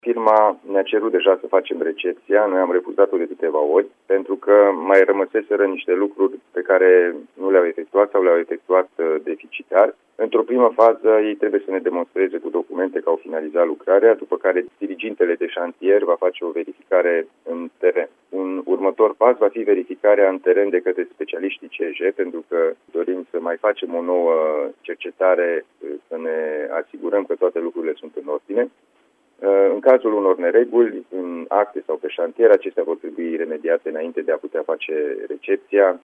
Precizarea a fost făcută, la Radio Timișoara, de președintele Consiliului Județean Timiș, Alin Nica.
Alin-Nica-drum.mp3